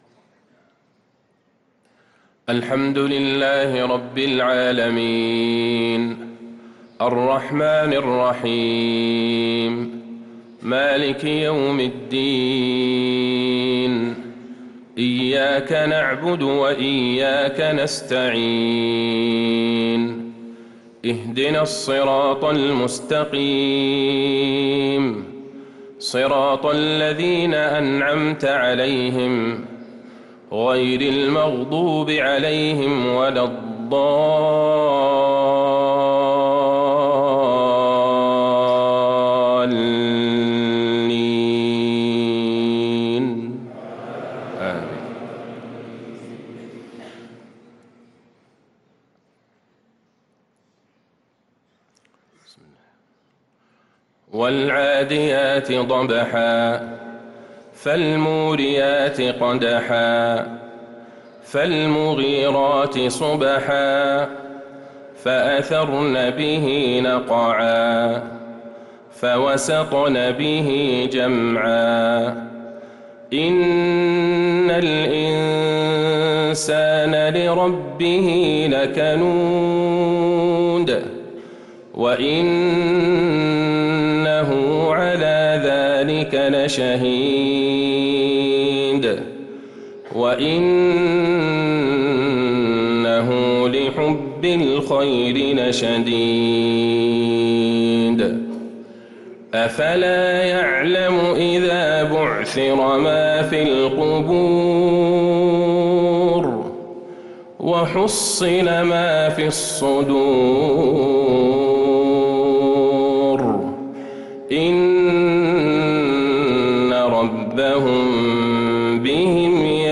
صلاة المغرب للقارئ عبدالله البعيجان 13 رجب 1445 هـ
تِلَاوَات الْحَرَمَيْن .